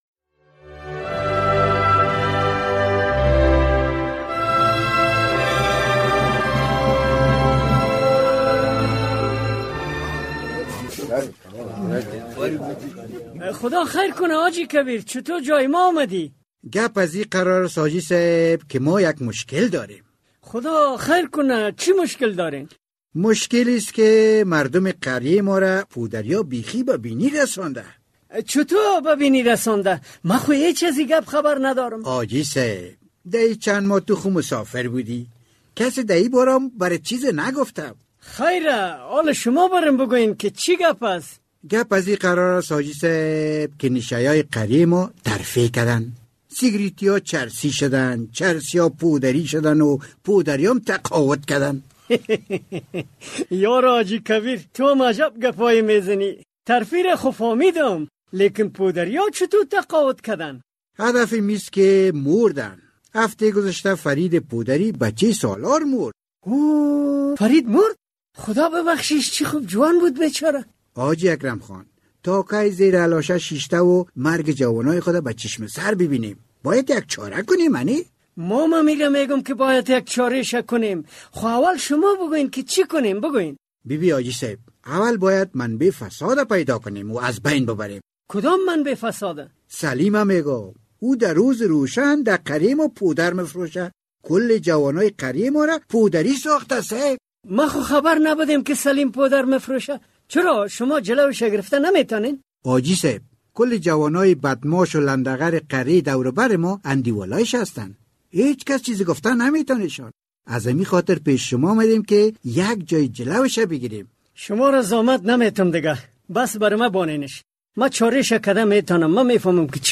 درامه کاروان زهر